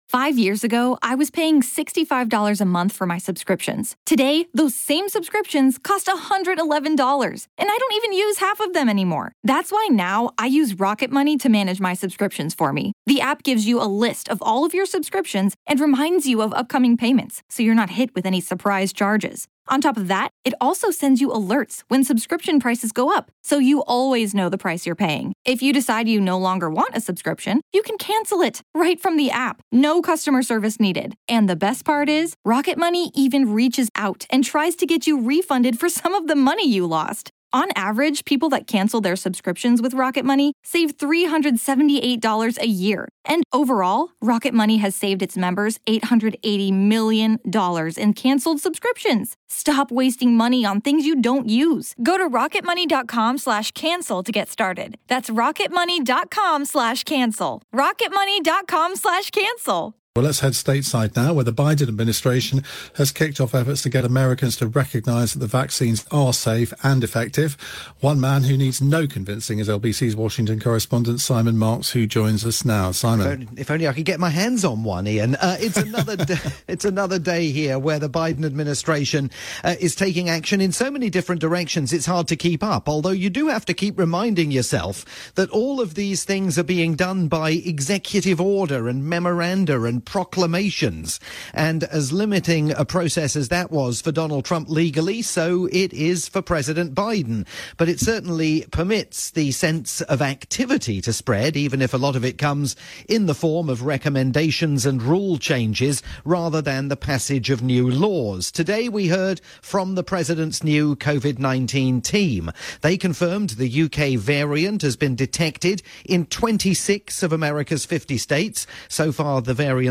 live report for Iain Dale's nightly "NewsHour" on LBC.